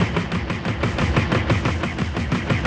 Index of /musicradar/rhythmic-inspiration-samples/90bpm
RI_DelayStack_90-02.wav